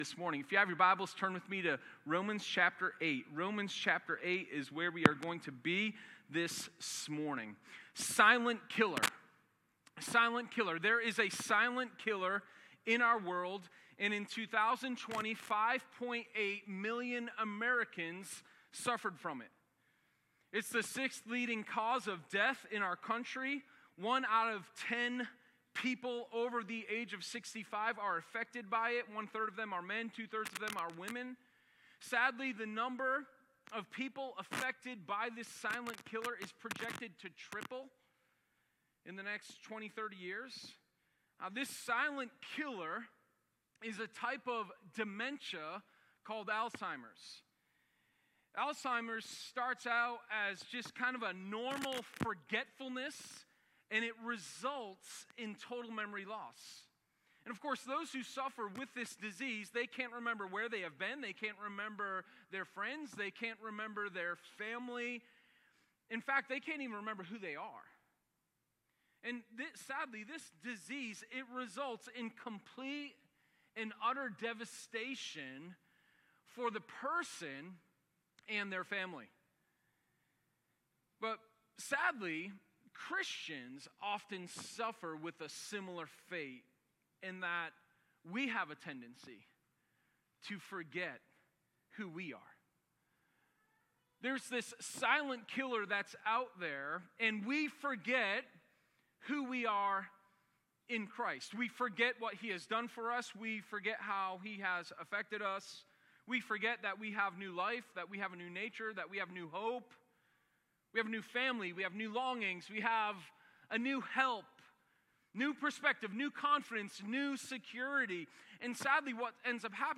Sermon04_11_New-Life.m4a